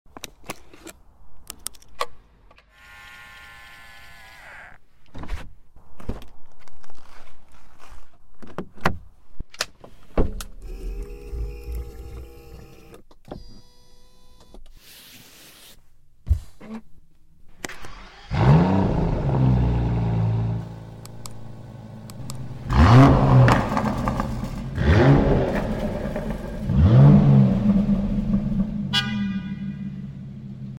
( $492K ) ASMR 1 sound effects free download